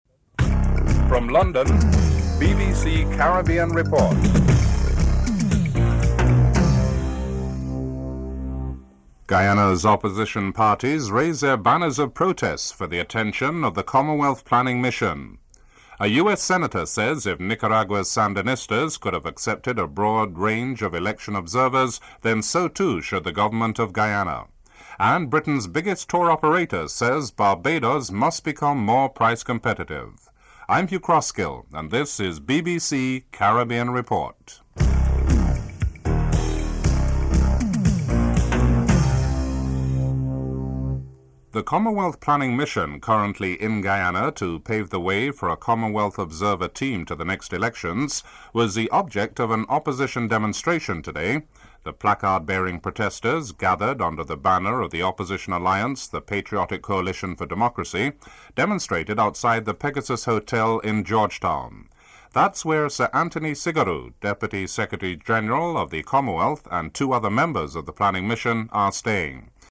1. Headlines (00:00-00:43)
Interview with Bob Graham (05:23-08:33)
4. Financial News (08:34-09:21)